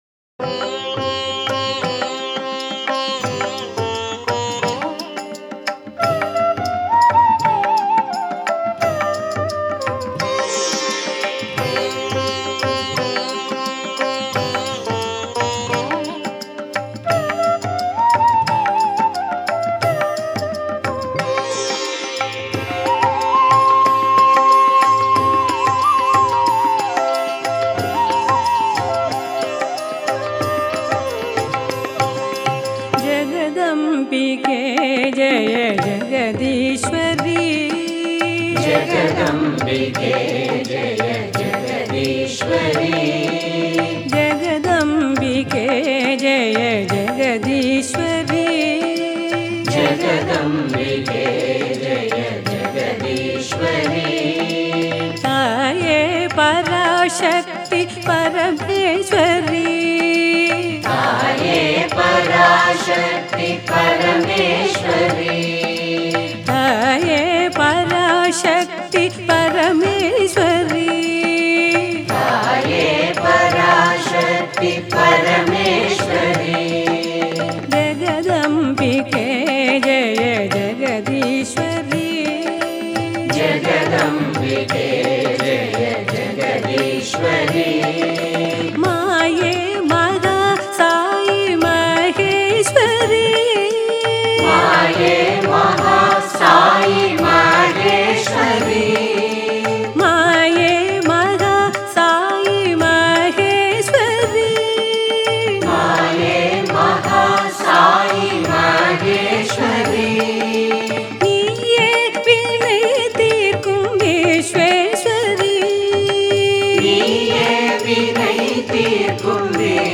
Author adminPosted on Categories Devi Bhajans